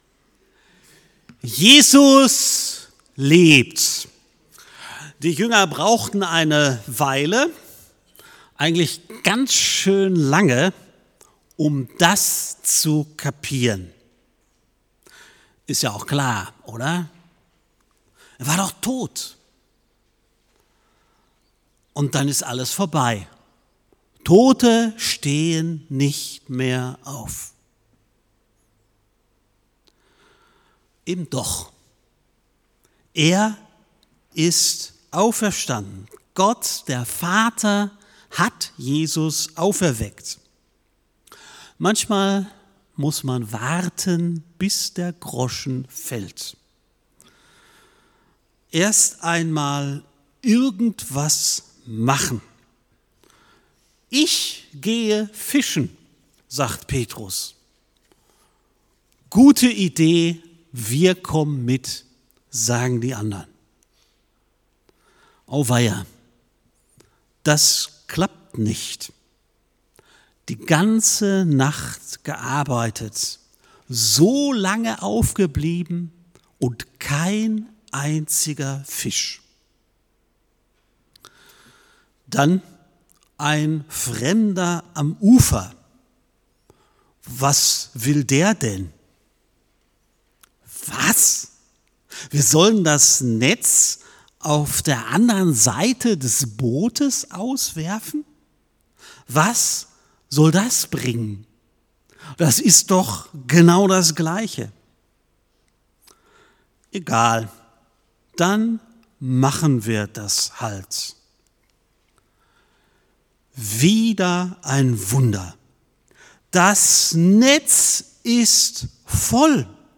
Ostergottesdienst